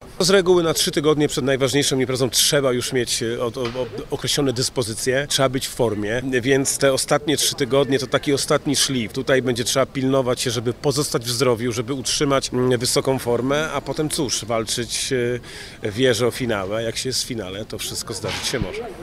– Mistrzostwa Polski będą próbą generalną przed halowym czempionatem globu, który pod koniec marca również odbędzie się w Toruniu – mówi prezes PZLA Sebastian Chmara.